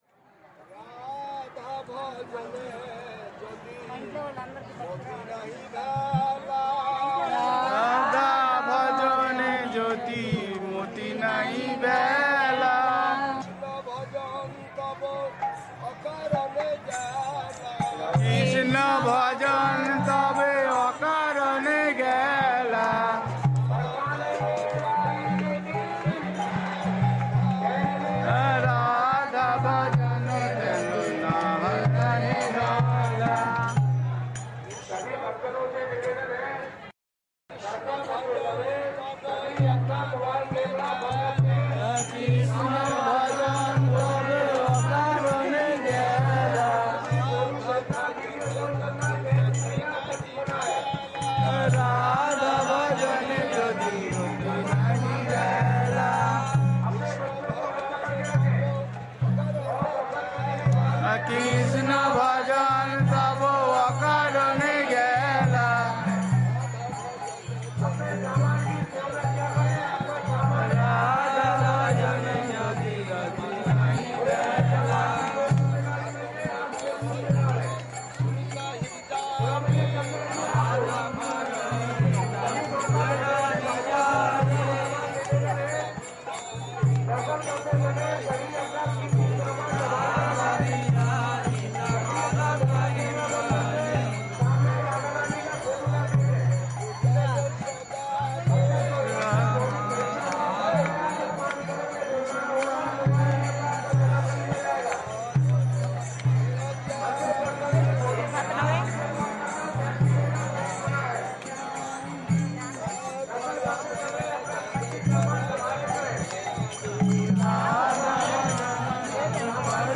(2/2) Sri Vrindavan Dham Parikrama: Sri Varsana Dham
CLICK TO LISTEN TO THE LECTURE Size: 8 Mb Length: 17 min Language: Bengali Sri Guru: Medium and Shelter 'Do you know why you take initiation?